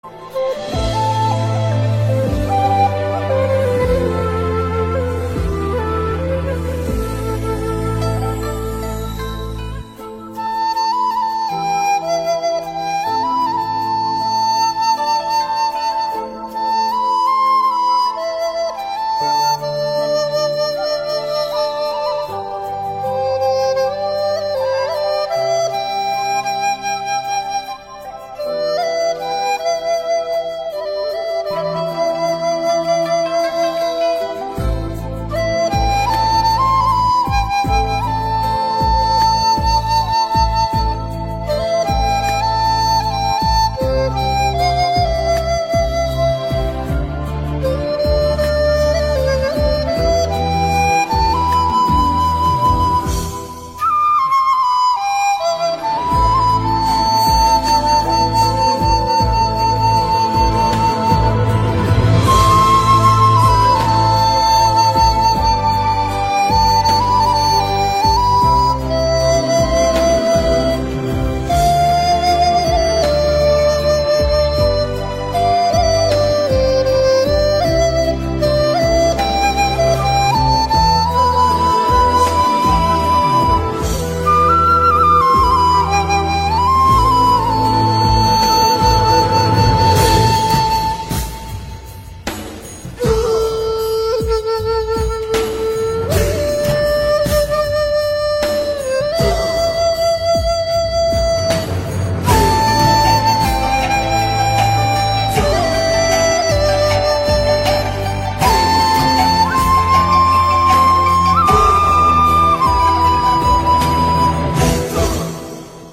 giai điệu hào hùng và da diết.
bản không lời chất lượng cao